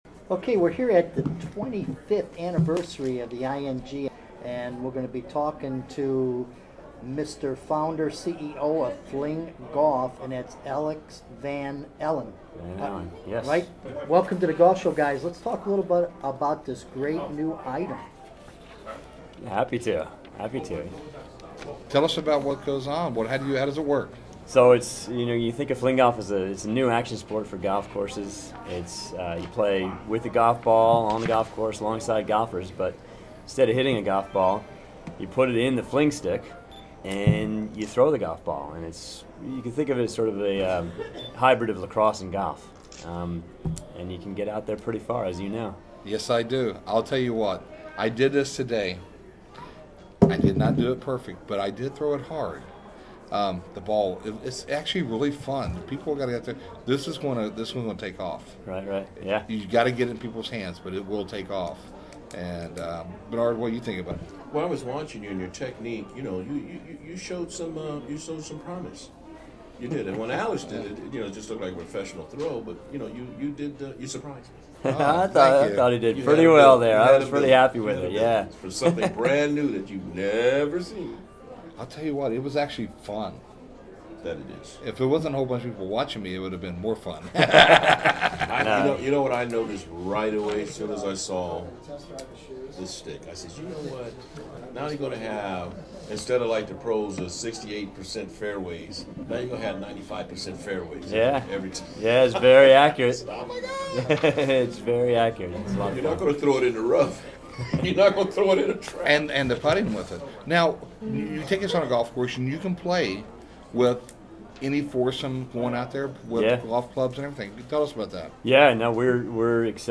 FlingGolf radio interview